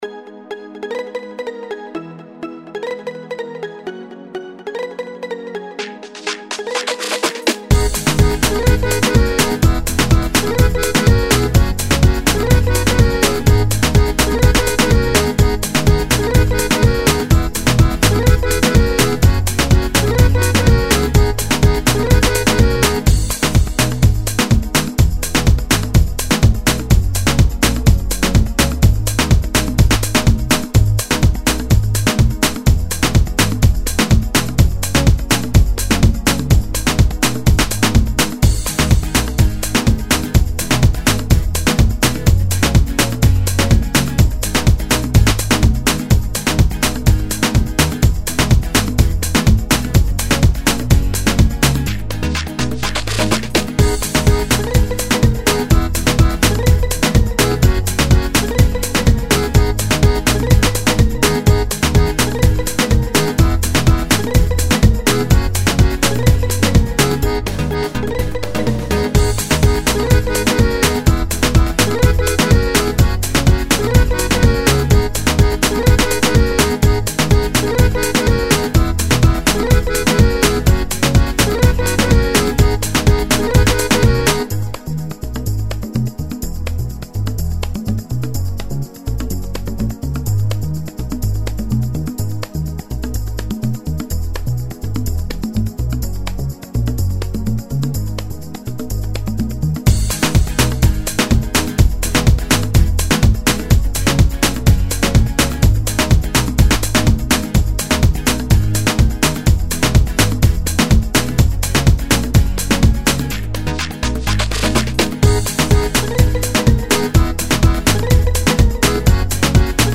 МУЗЫКА